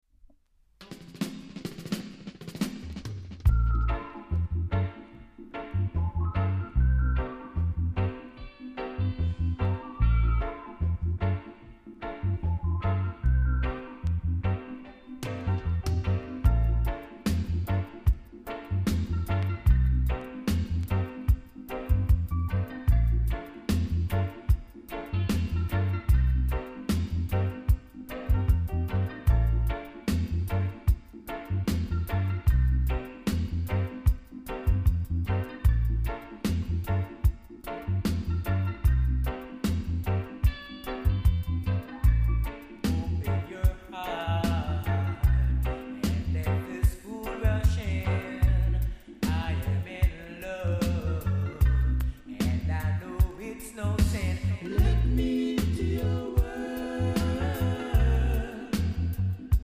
※裏面の中盤〜後半にゴミの詰まりみたいなのがあり所々パチノイズあります。